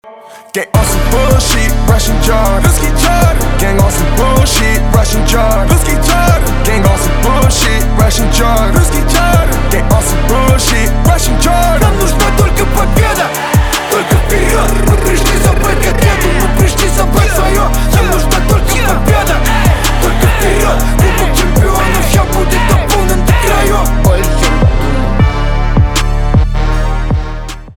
русский рэп
битовые , басы , качающие
trap